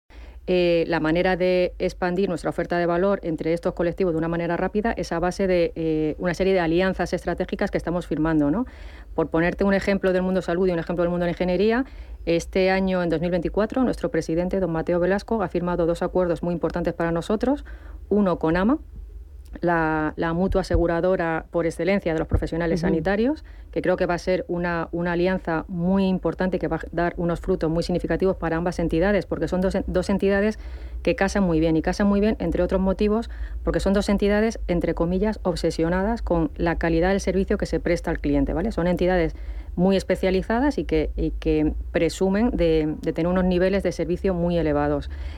entrevista_CBNK_jul2024.mp3